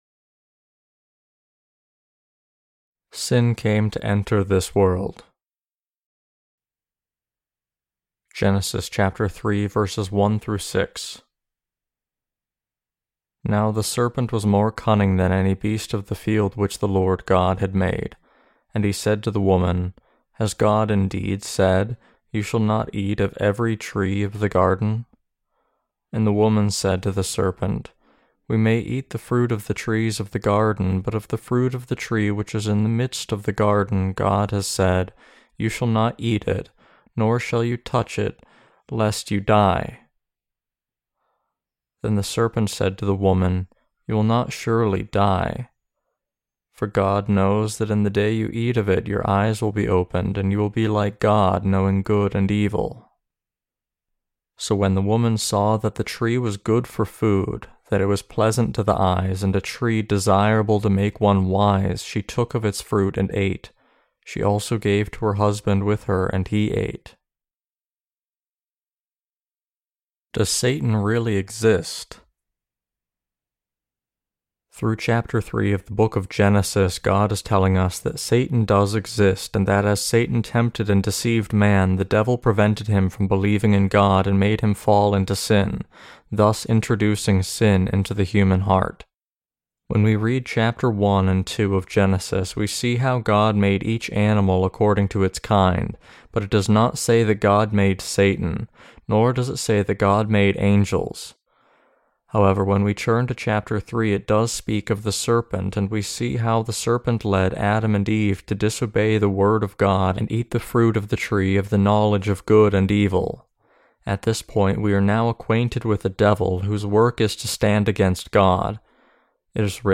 Sermons on Genesis (II) - The Fall of Man and The Perfect Salvation of God Ch3-2.